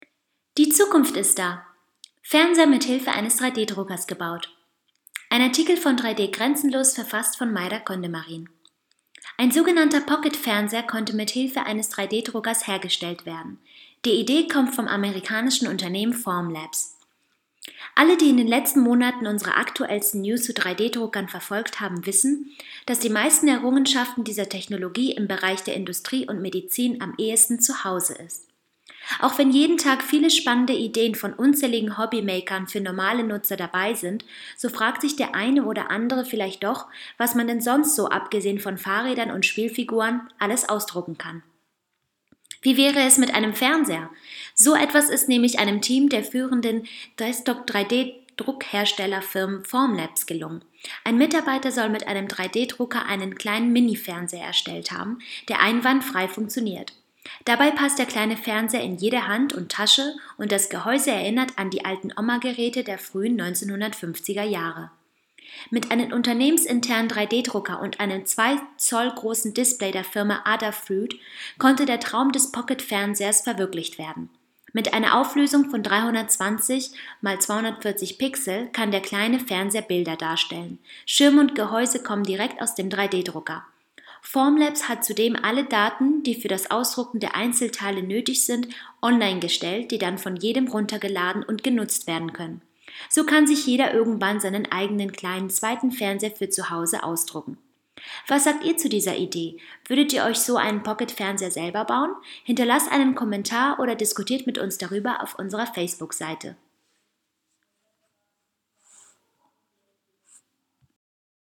news_vorlesen_lassen_fernseher_aus_dem_3d-drucker.mp3